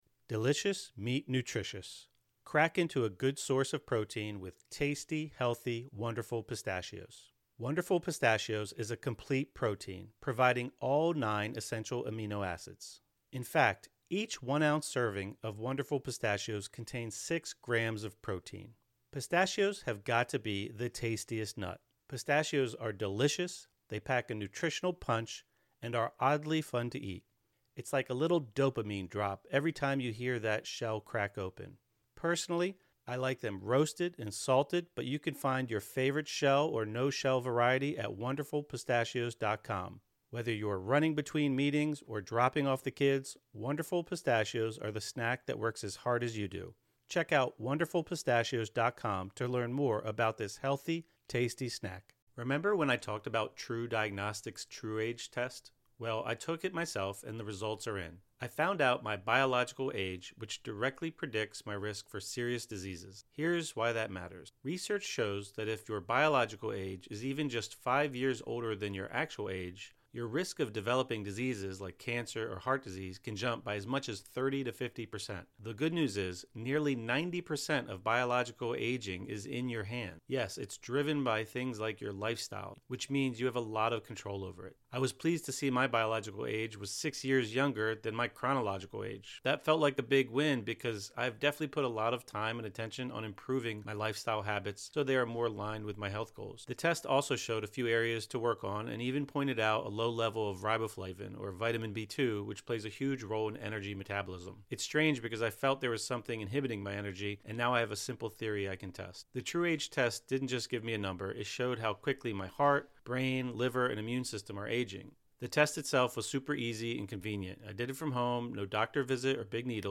Finding Your Purpose in Midlife: A Conversation